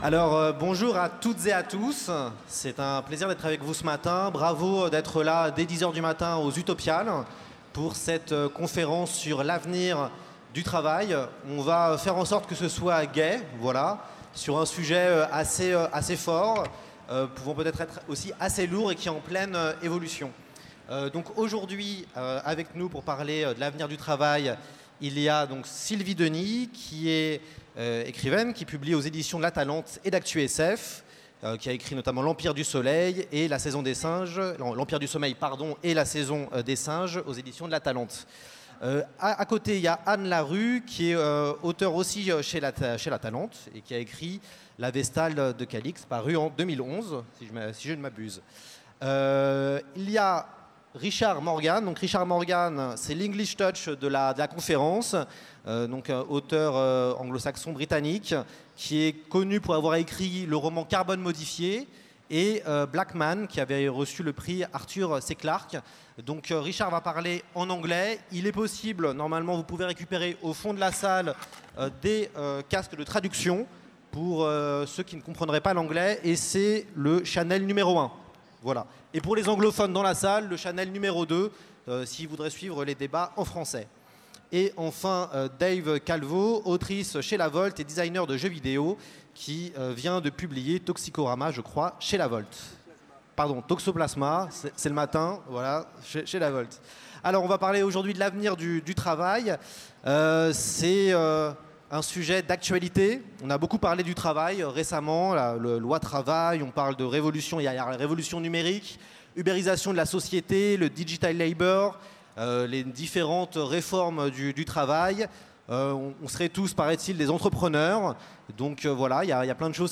Utopiales 2017 : Conférence L’avenir du travail